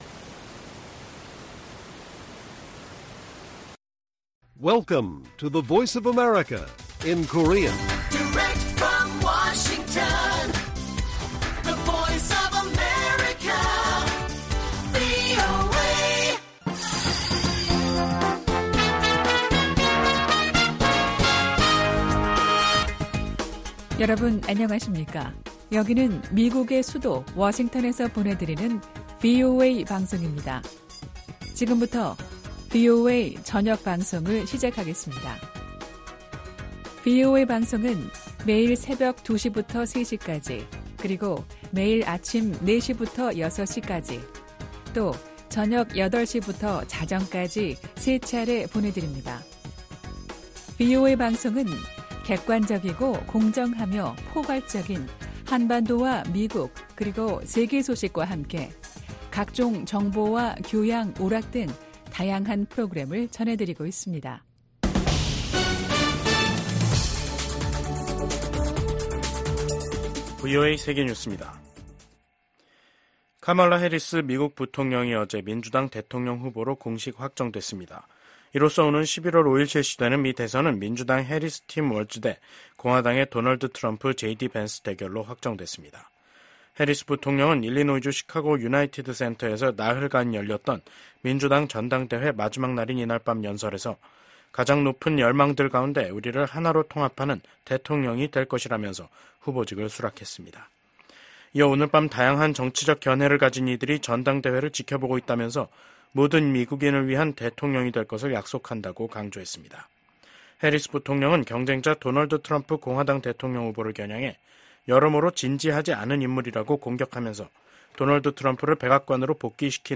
VOA 한국어 간판 뉴스 프로그램 '뉴스 투데이', 2024년 8월 23일 1부 방송입니다. 카멀라 해리스 미국 부통령이 민주당의 대선 후보 수락 연설에서 모든 미국인을 위한 대통령이 되겠다며 분열된 미국을 하나로 통합하겠다는 의지를 밝혔습니다. 북한과 중국, 러시아가 인접한 중국 측 지대에 길이 나고 구조물이 들어섰습니다. 김정은 국무위원장 집권 후 엘리트층 탈북이 크게 늘어난 것으로 나타났습니다.